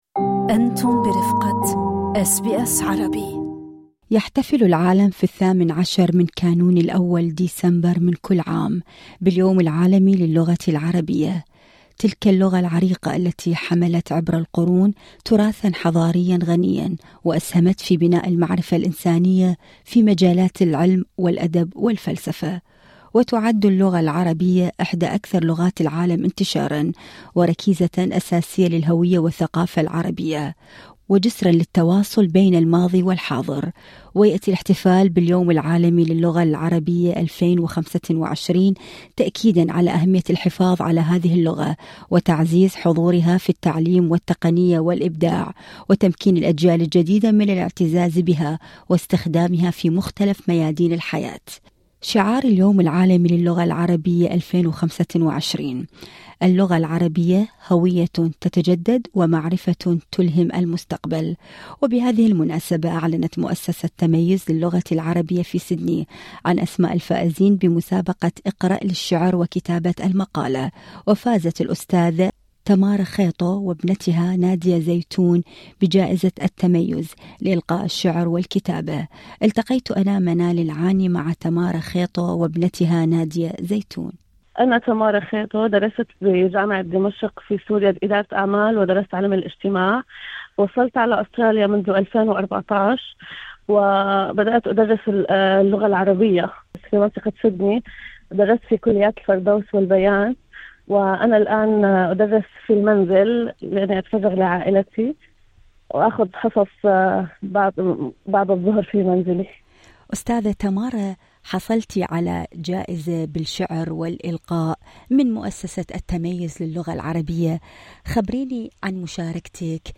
التفاصيل في اللقاء الصوتي اعلاه